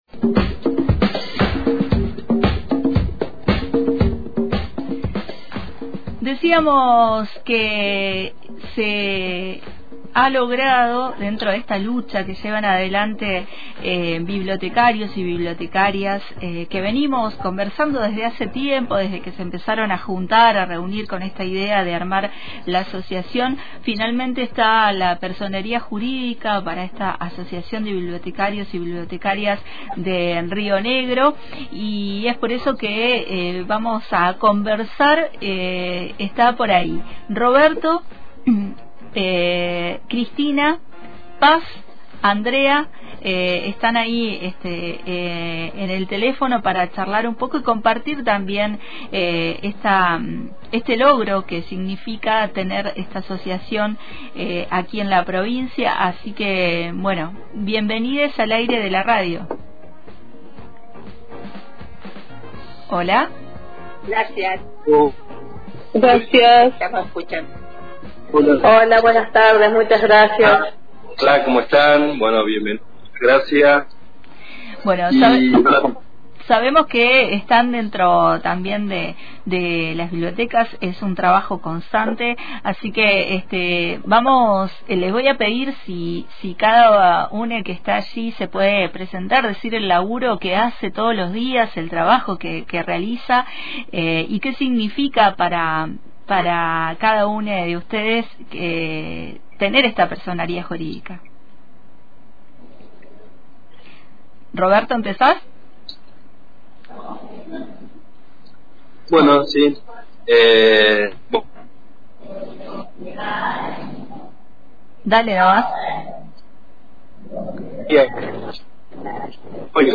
Dialogamos con integrantes de ABBRIN, Asociación de Bibliotecarias y Bibliotecarios de la provincia de Rio Negro, para compartir la invitación al recibimiento de su personería jurídica en la Biblio…
Entrevista-colectiva-con-Asociacion-de-Bibliotecarios-y-Bibliotecarias-de-Rio-Negro.mp3